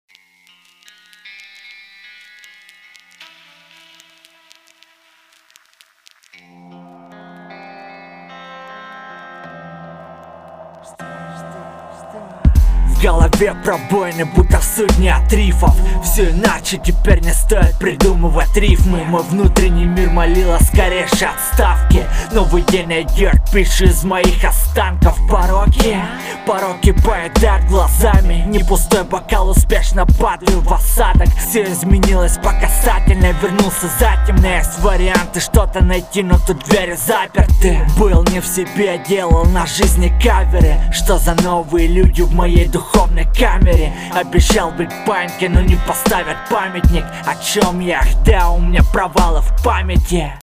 С битом неплохо сочетаешься, подача, текст.